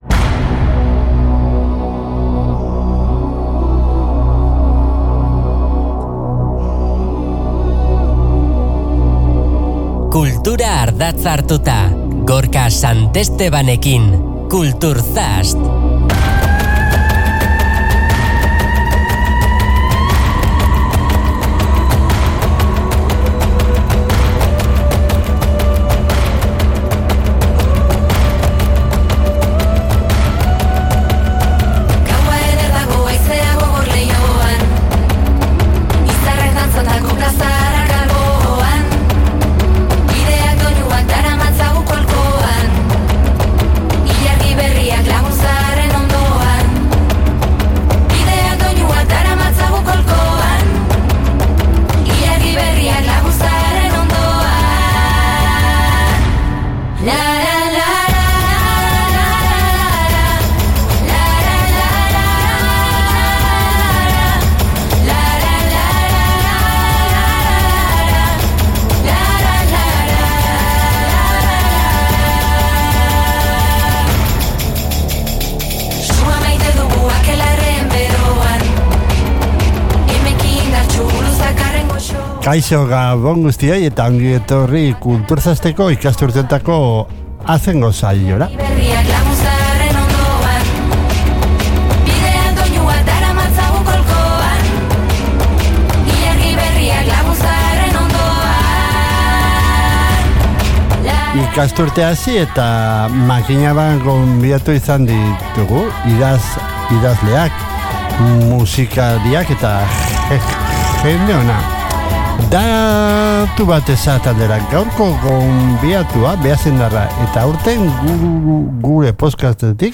Agendako hitzordu garrantzitsuak, kultur-egileei elkarrizketak eta askoz ere gehiago bilduko ditu ordubeteko tartean.